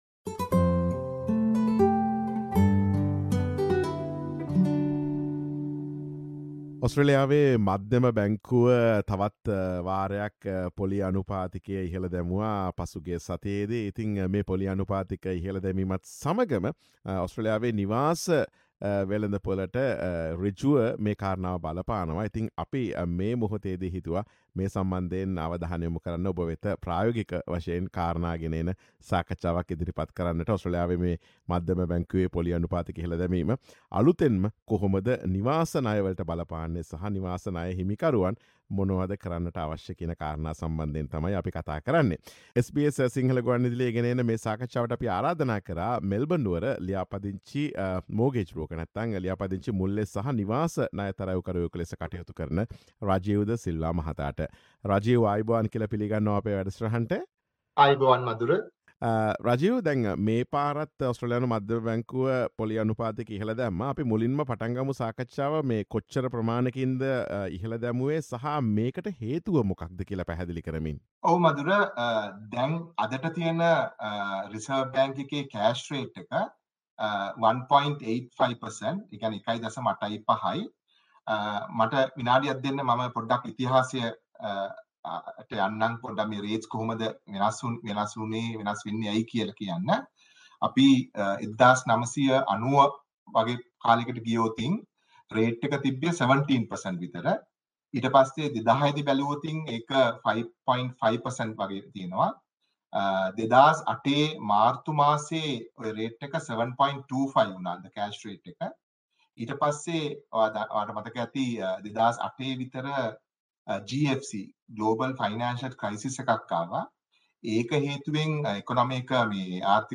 Listen to SBS Sinhala Radio interview on the relief available to mortgage holders and the steps they can take in the face of rising interest rates in Australia.